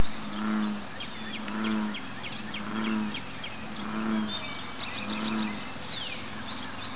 Bullfrog Sounds
I took my little pencam/recorder out on the greenway at dawn.
It is a noisy place, but amid the birds, waterfall and distant traffic, I got a pretty good recording of a bullfrog.
Bullfrog - (just a few seconds of sound)
Bullfrog.wav